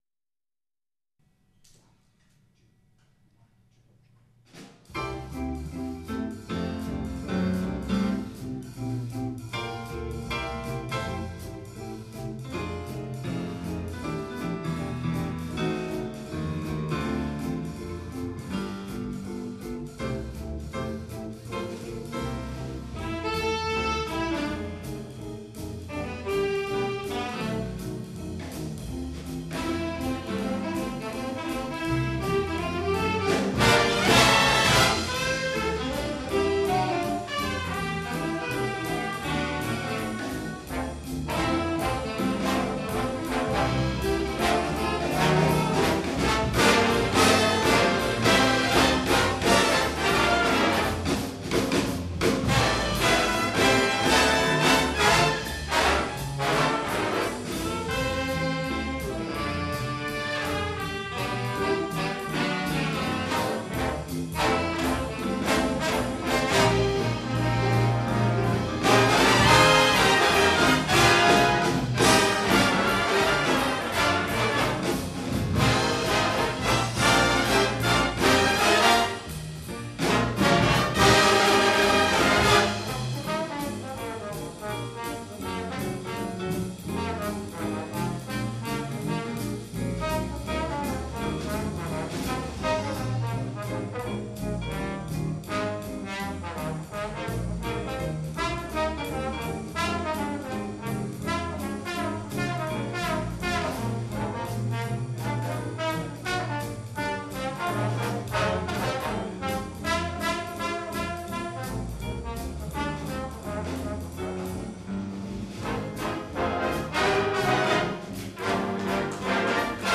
· Genre (Stil): Big Band